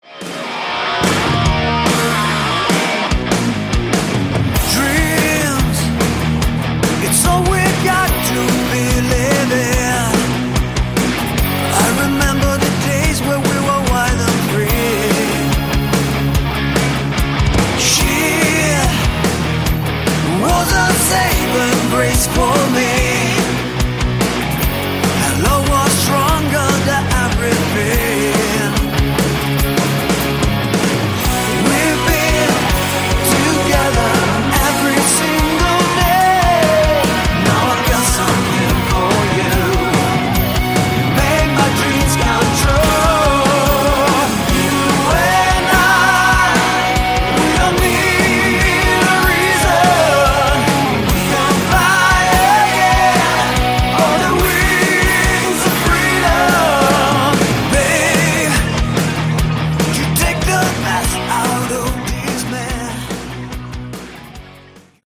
Category: Melodic Hard Rock
lead and backing vocals
rhythm guitars, lead and acoustic guitars
lead guitars, guitar solos
bass
drums